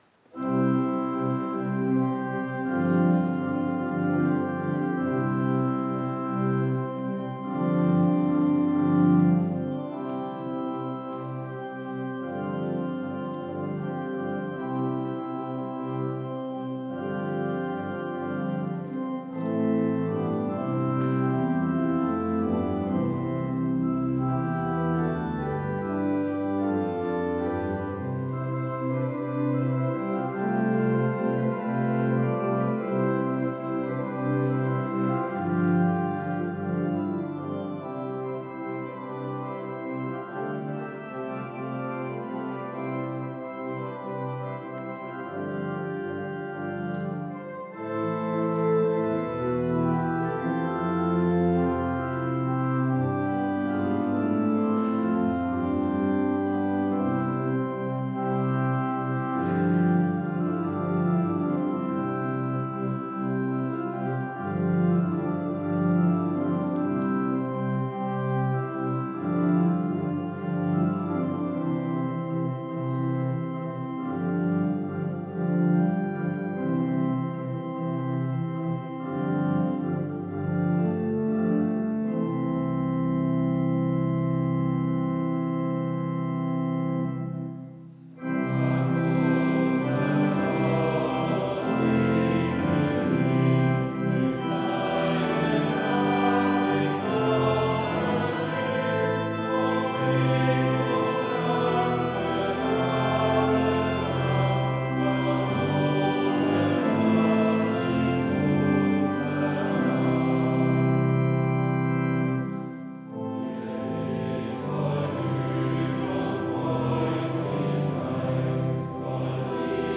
Audiomitschnitt unseres Gottesdienstes vom 2. Sonntag nach Ostern 2025.